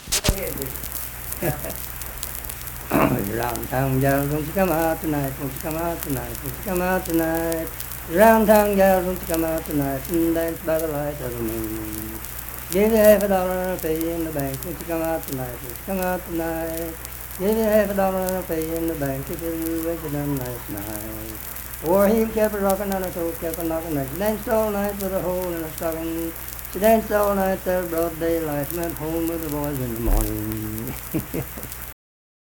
Unaccompanied vocal music
Dance, Game, and Party Songs
Voice (sung)
Harts (W. Va.), Lincoln County (W. Va.)